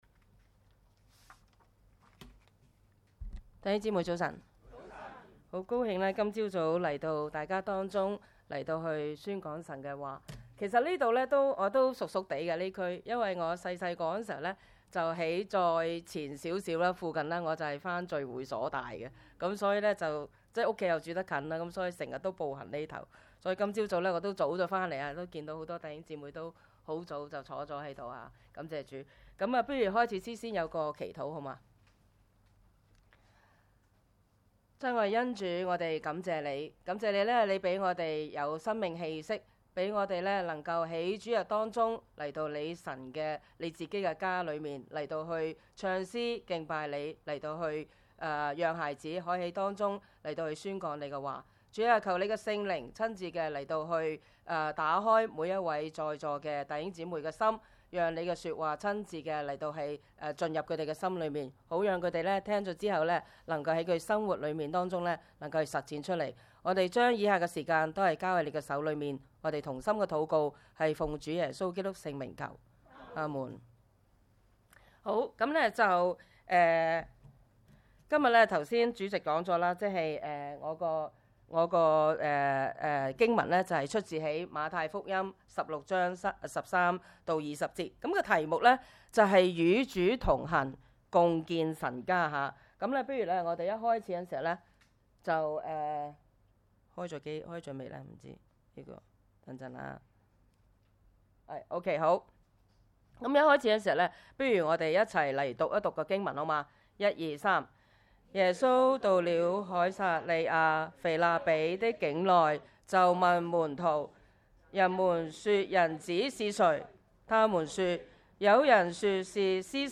崇拜